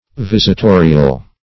Visitorial \Vis`it*o"ri*al\, a.